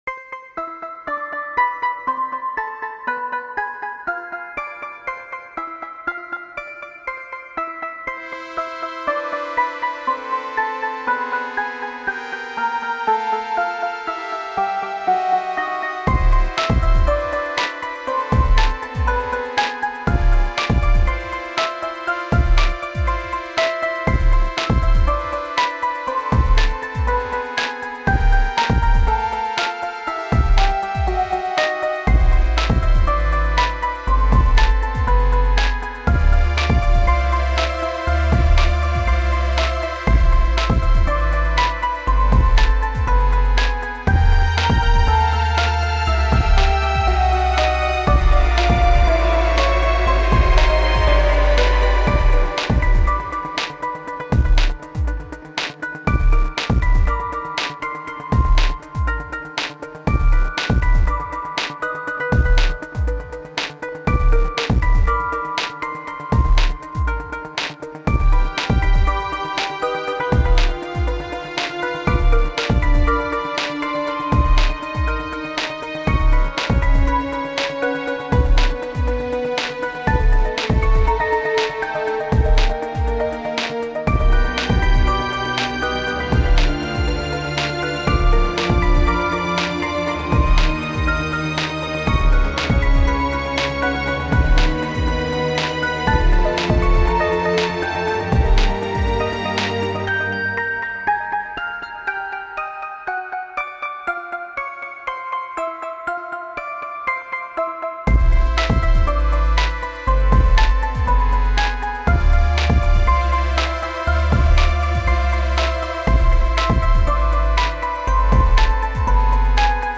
Cheery bells glitch gloriously to sinister rhythms.